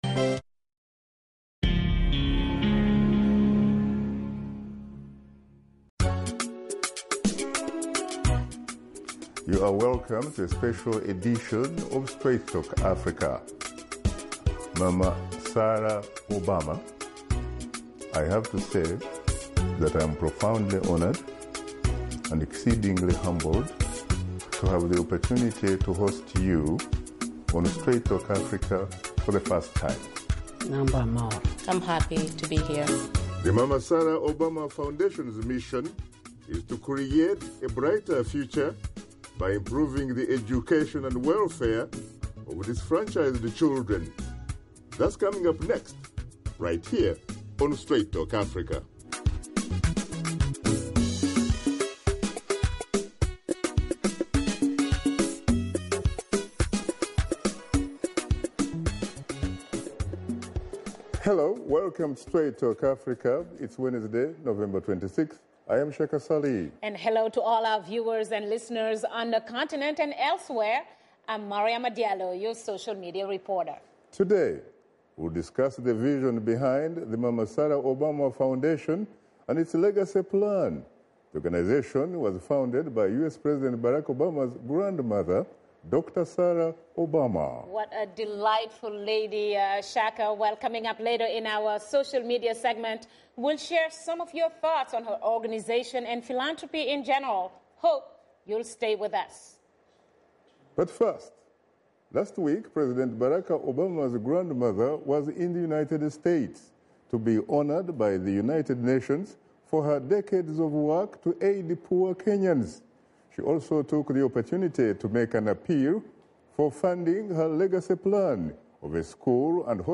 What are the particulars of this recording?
Pre- Taped Interview